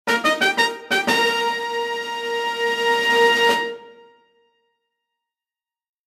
fanfare music